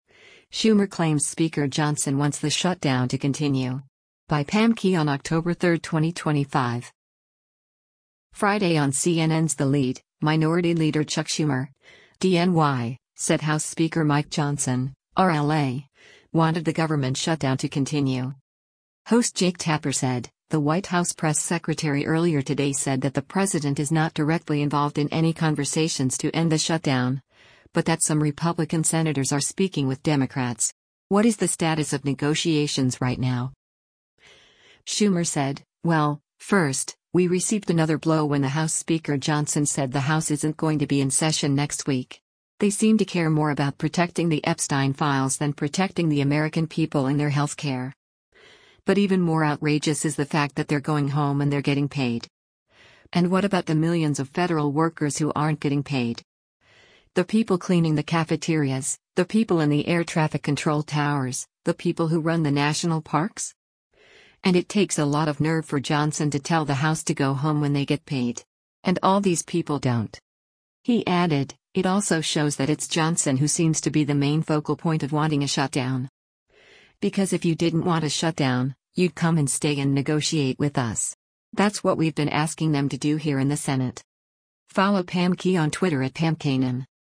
Friday on CNN’s “The Lead,” Minority Leader Chuck Schumer (D-NY) said House Speaker Mike Johnson (R-LA) wanted the government shutdown to continue.